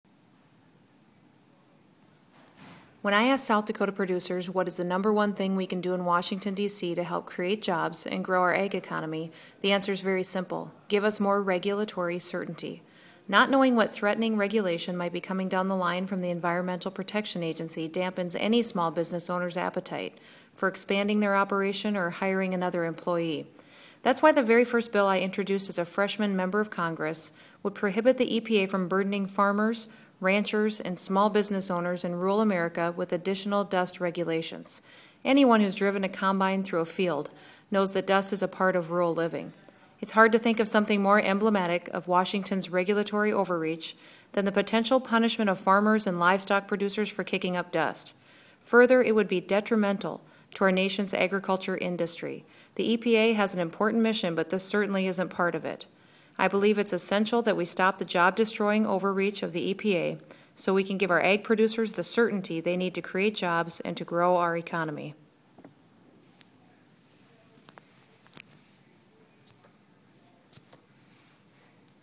The Ag Minute, guest host Rep. Kristi Noem discusses H.R. 1633, The Farm Dust Regulation Prevention Act of 2011, which she introduced in April. The bill would block the Environmental Protection Agency (EPA) from imposing a stricter dust standard on farmers and ranchers.
The Ag Minute is Chairman Lucas's weekly radio address that is released from the House Agriculture Committee.